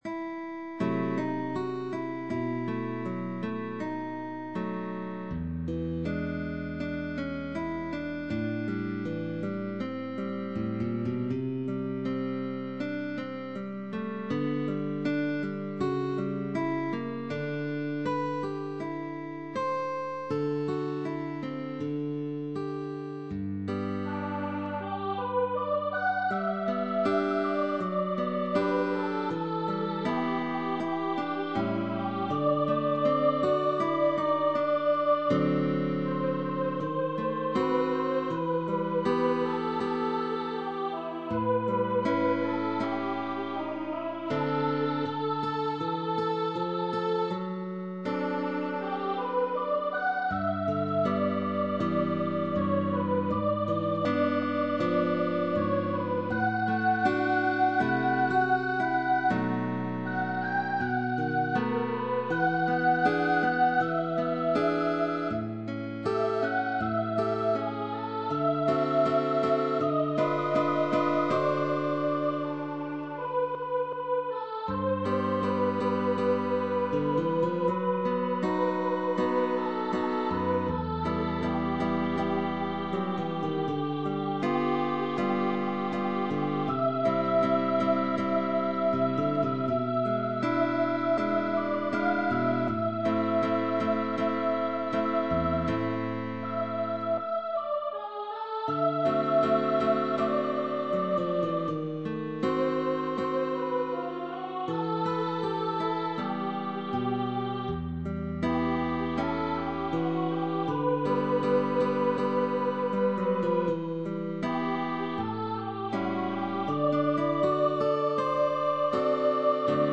voz (soprano o tenor) con acompañamiento de guitarra.